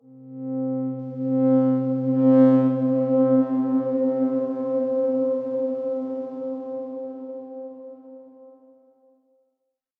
X_Darkswarm-C3-pp.wav